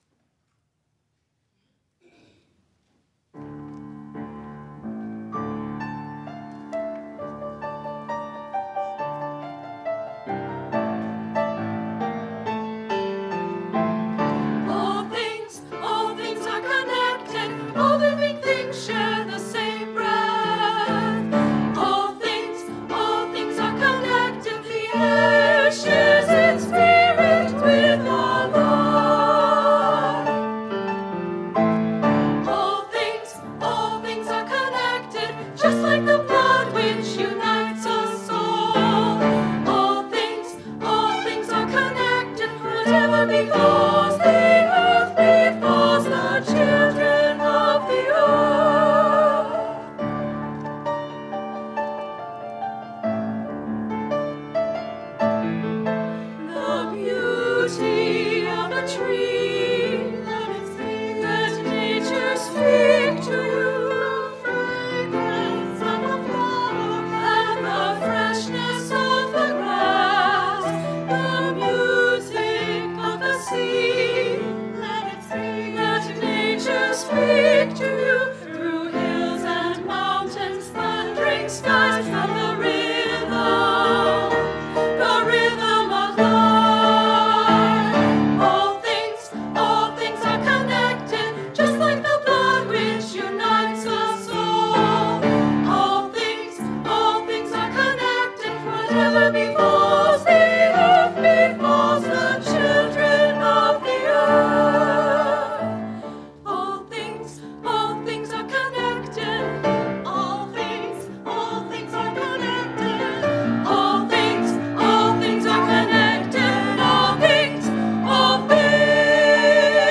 Choir and Instrumental Music
Beautiful small ensemble music from UUSS’s Chalice Singers on October 6, 2019.